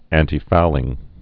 (ăntē-foulĭng, ăntī-)